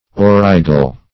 Aurigal \Au*ri"gal\